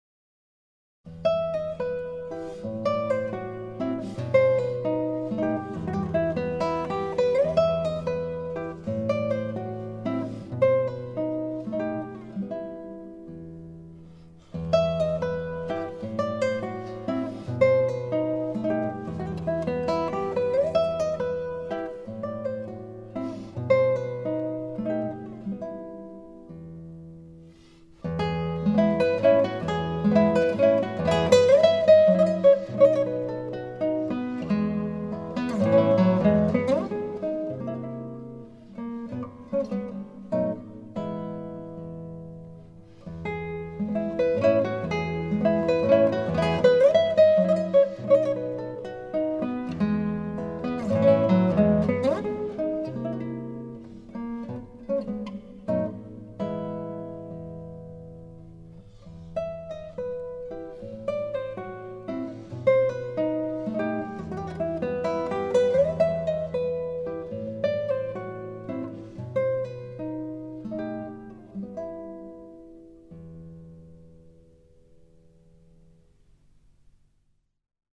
0118-吉他名曲阿狄丽达.mp3